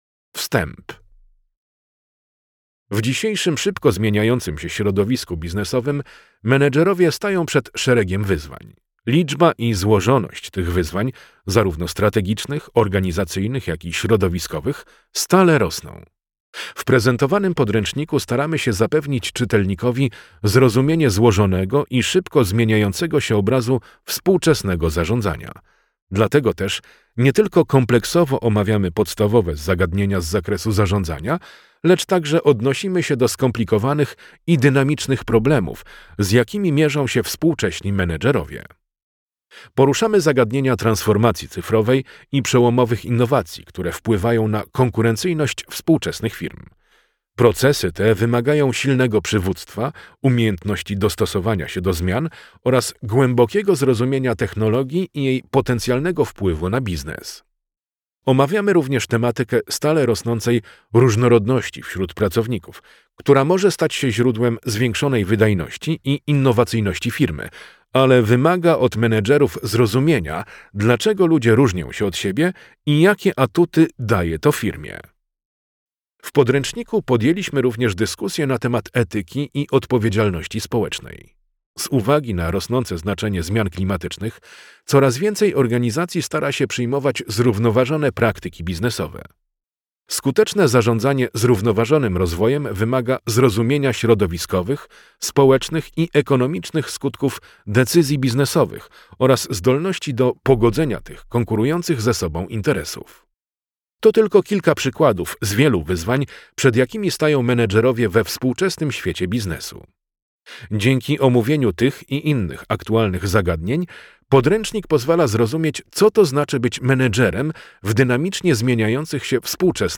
Zarządzanie. Nowe otwarcie - praca zbiorowa - audiobook